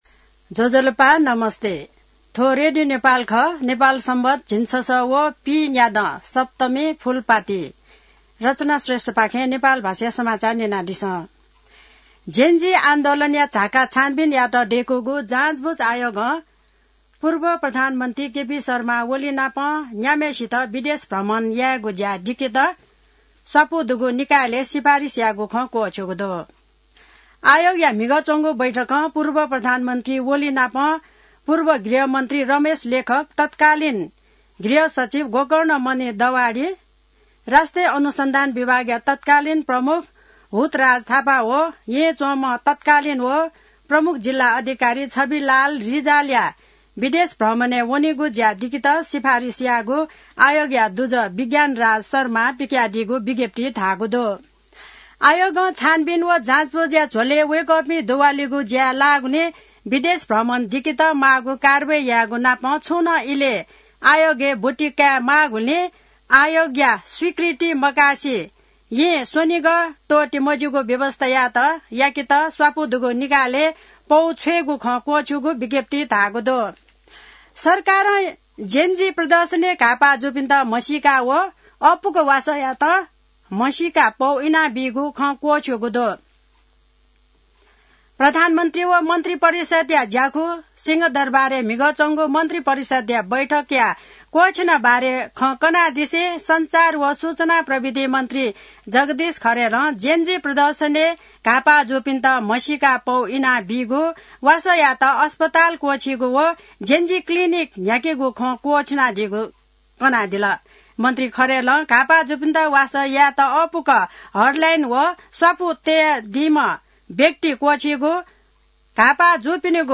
नेपाल भाषामा समाचार : १३ असोज , २०८२